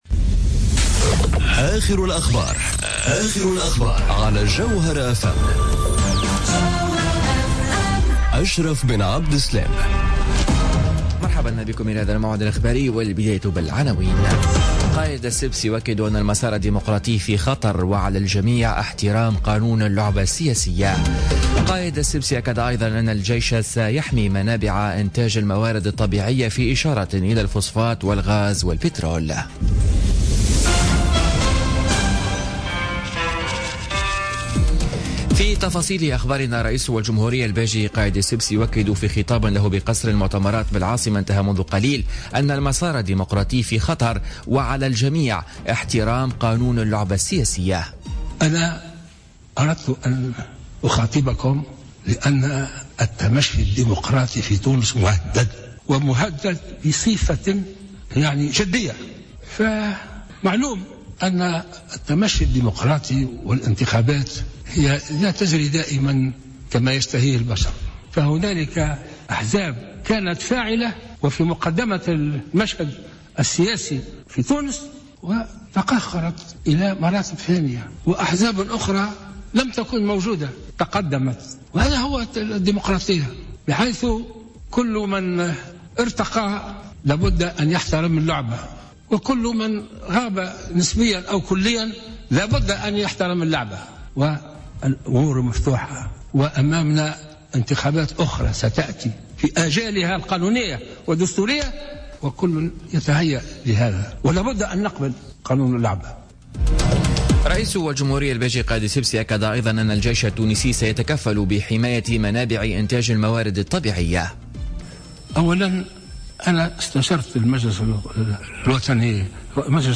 نشرة أخبار منتصف النهار ليوم الإربعاء 10 ماي 2017